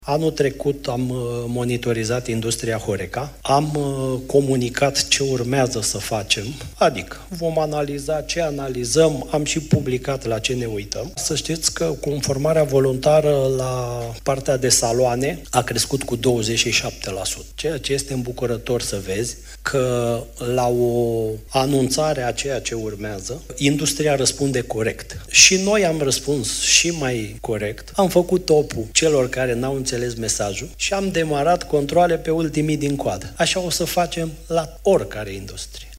Șeful Fiscului, Adrian Nica, a făcut declarații la Conferința anuală privind taxele, organizată de o firmă de consultanță, Price Waterhouse Coopers.
Președintele ANAF, Adrian Nica: „Conformarea voluntară la partea de saloane a crescut cu 27%”